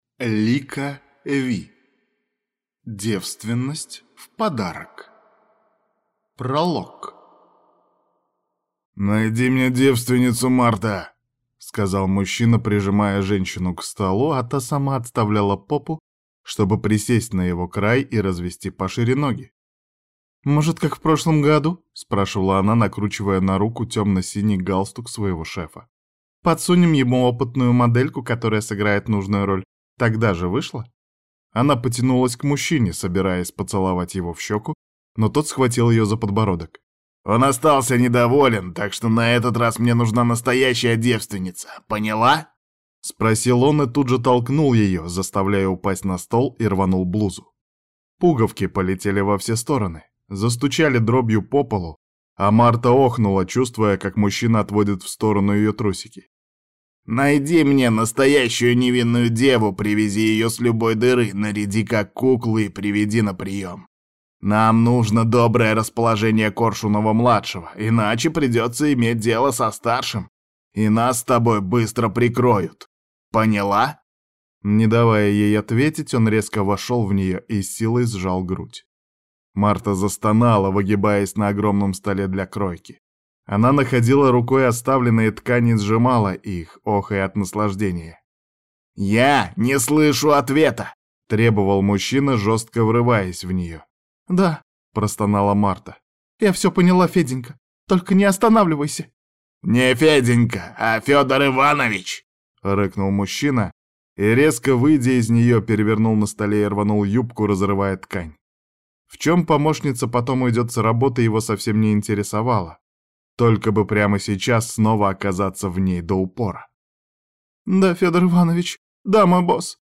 Аудиокнига Девственность в подарок | Библиотека аудиокниг